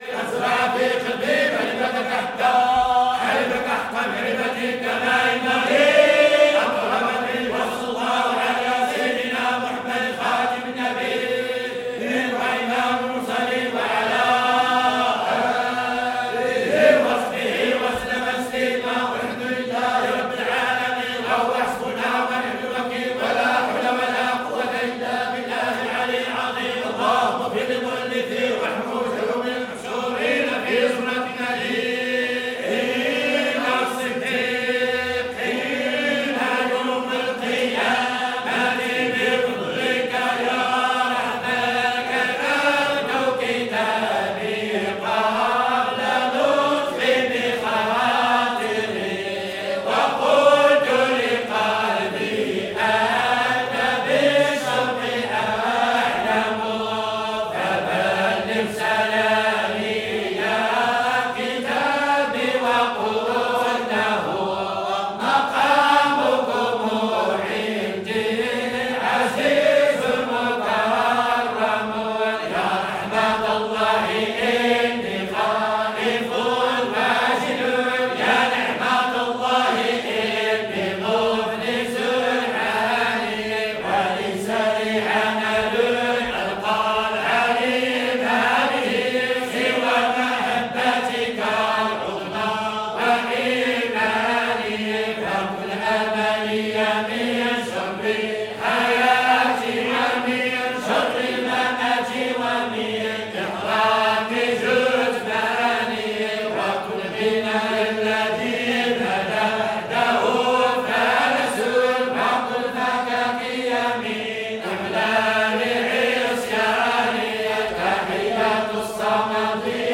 Chanter du Prophète, adorer Muḥammad
Les récitations ou les chants en l’honneur du Prophète Muḥammad font office d’une pratique dévotionnelle dans l’islam au Maroc et au Maghreb. Ces formes vocales sont organisées le plus souvent au sein des confréries soufies ; elles ont lieu dans les zawiyas (les loges des confréries) et plus rarement dans les mosquées ou dans des lieux privés.
Il est vrai que, par exemple, dans la récitation collective du livre des prières Dalā’il, la forme vocale, dépouillée, se limite à une corde de récitation plus ou moins variée, accélérée ou ralentie.